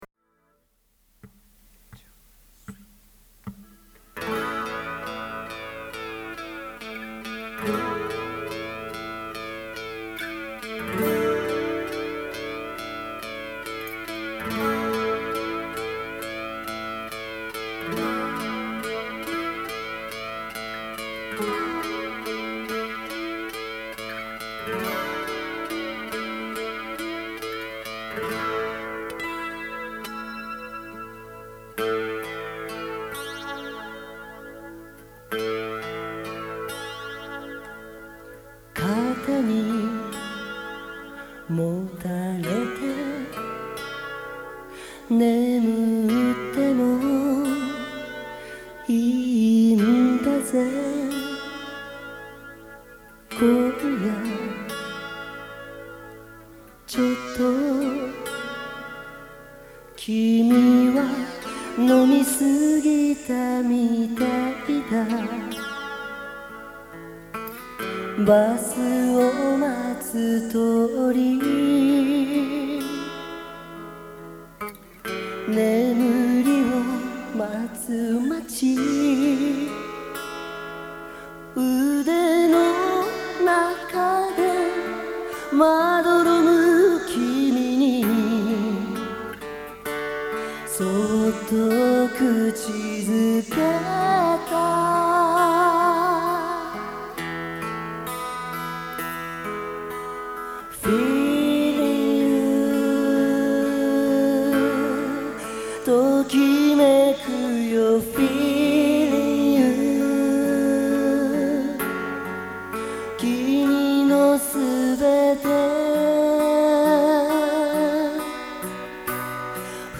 1985年9月22日に行われた記念コンサート
MORE「Feeling」